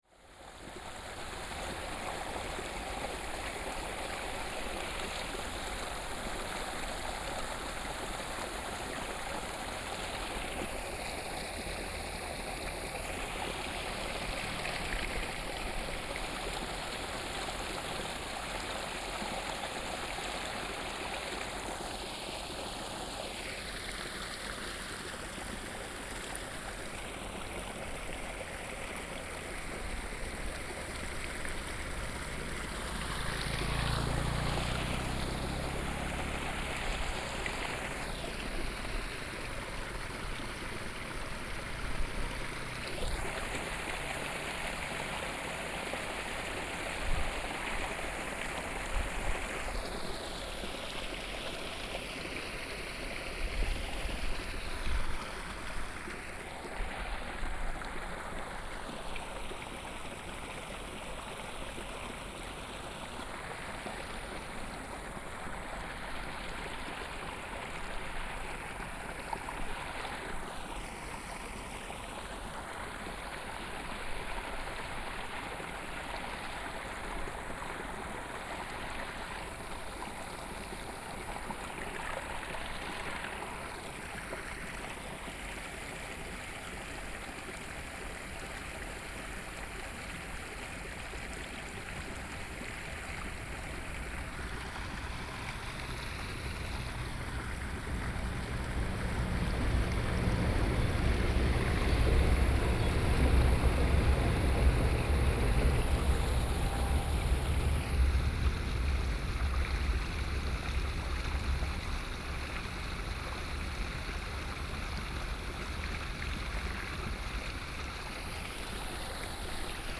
In the farmland ,Traffic Sound ,Farmland irrigation waterways ,The sound of water ,Train traveling through Binaural recordings ,Best with Headphone, Proposal to turn up the volume ,Sony PCM D100+ Soundman OKM II